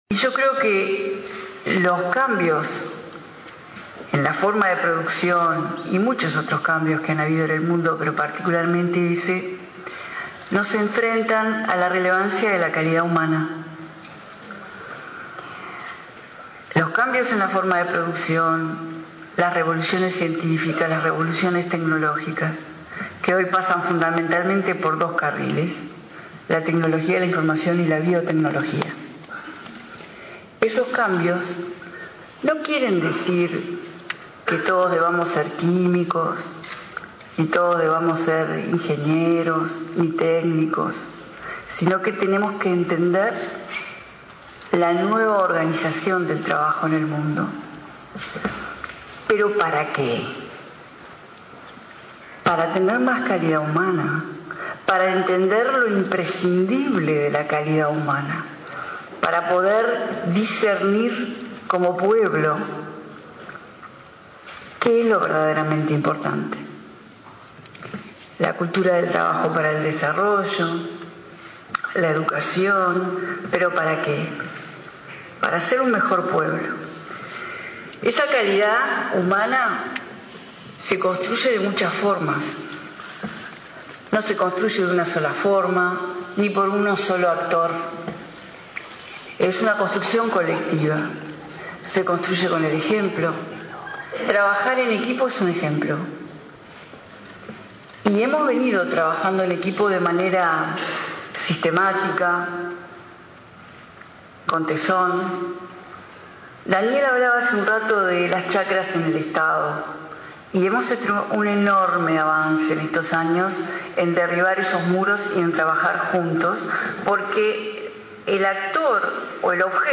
La ministra de Industria, Carolina Cosse, sostuvo que los cambios en la forma de producción nos enfrentan a la relevancia de la calidad humana. Al disertar en un seminario sobre trabajo y empleo, que se realizó este martes en la Intendencia de Montevideo, dijo esa calidad humana se construye, por ejemplo, trabajando en equipo.